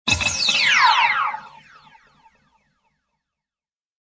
descargar sonido mp3 videojuegos 2